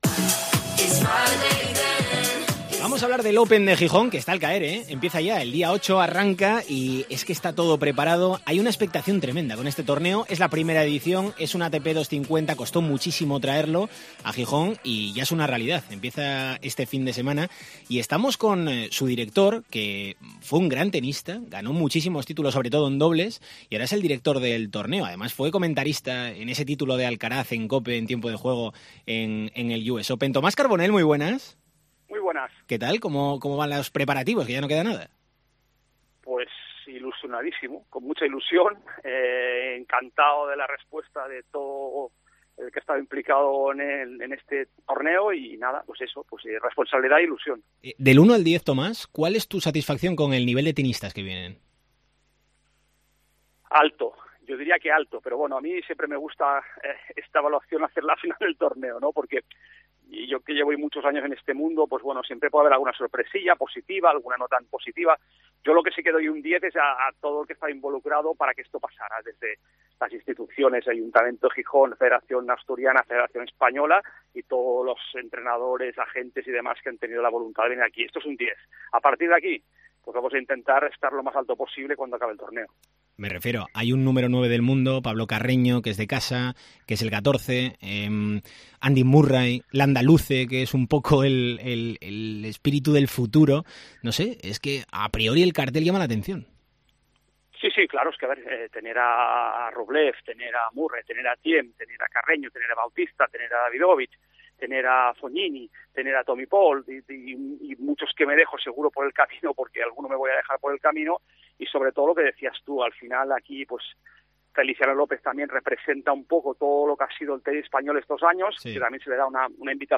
Entrevista a Tomás Carbonell, director del Open de Gijón